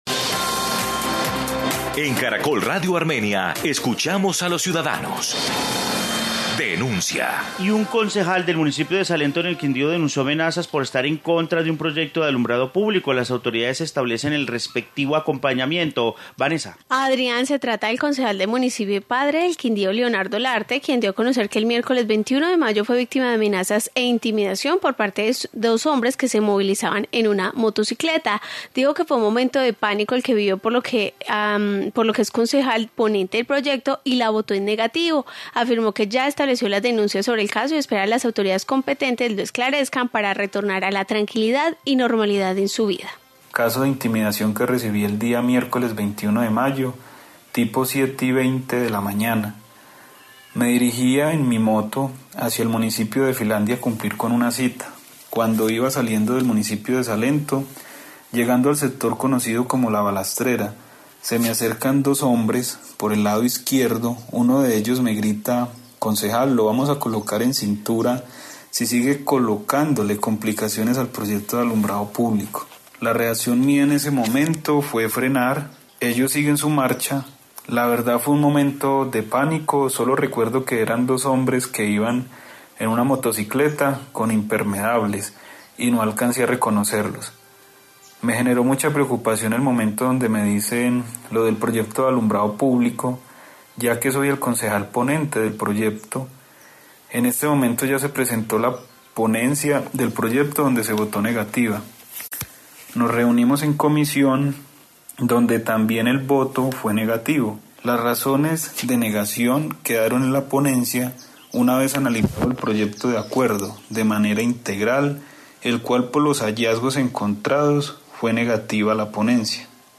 Informe amenazas Concejal de Salento